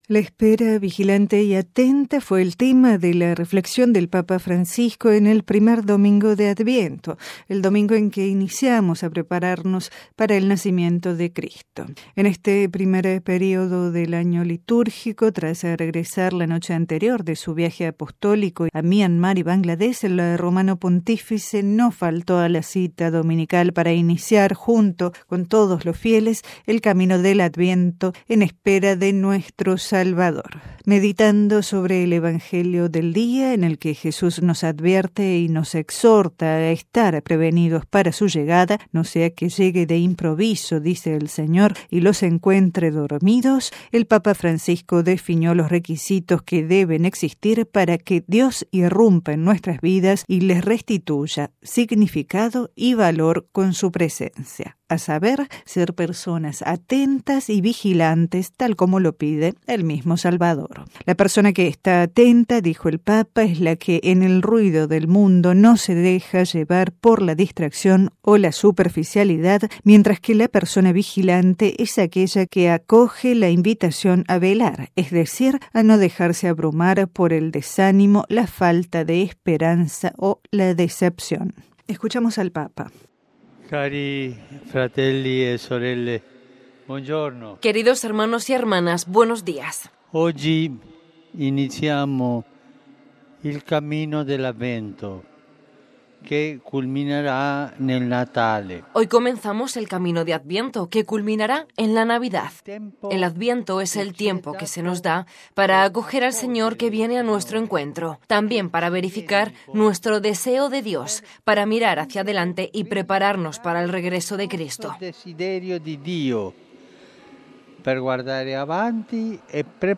A continuación, la alocución del Papa antes del rezo del Ángelus